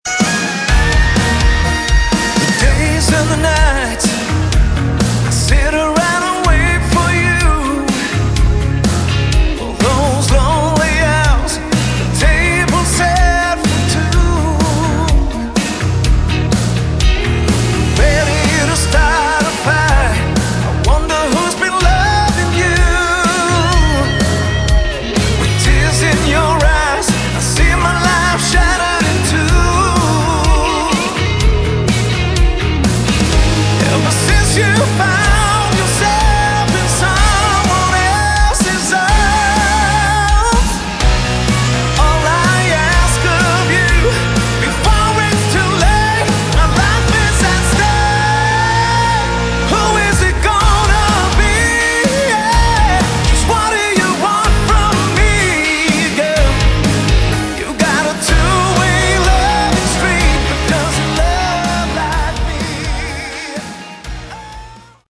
Category: Modern Hard Rock
lead vocals, backing vocals
saxophone